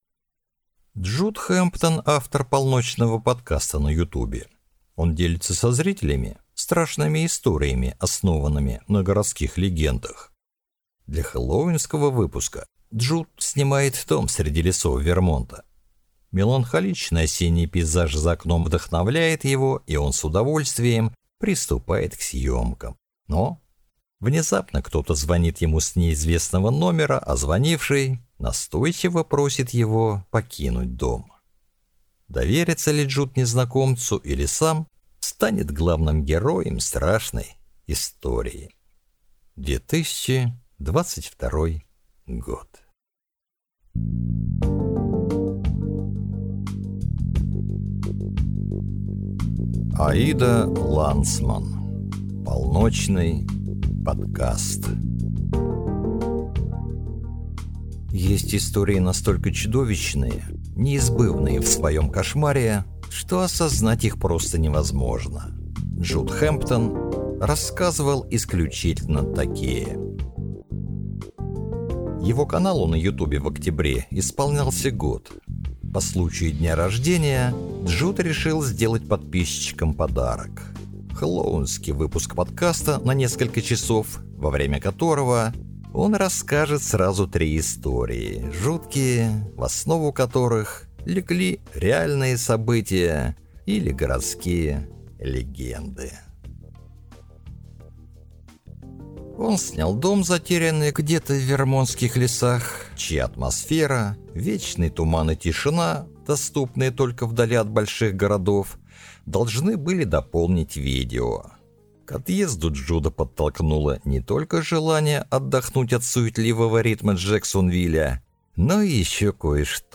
Аудиокнига Полночный подкаст | Библиотека аудиокниг
Прослушать и бесплатно скачать фрагмент аудиокниги